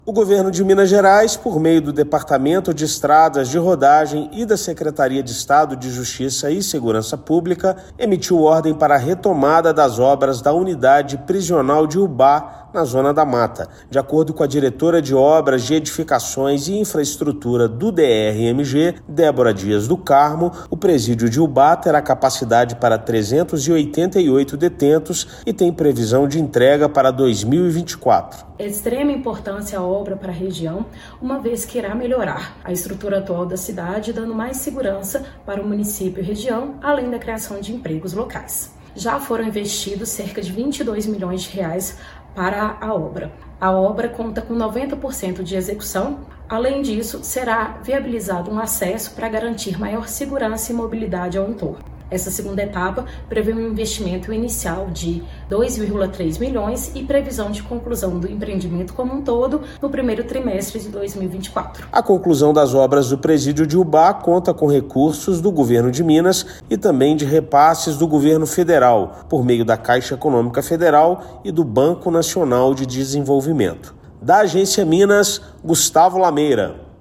[RÁDIO] Governo de Minas vai concluir obras da Cadeia Pública de Ubá, na Zona da Mata
O Governo de Minas, por meio do Departamento de Estradas de Rodagem do Estado de Minas Gerais (DER-MG) e da Secretaria de Estado de Justiça e Segurança Pública (Sejusp), emitiu ordem de início para a conclusão das obras de construção da unidade prisional masculina de Ubá, na Zona da Mata. Ouça matéria de rádio.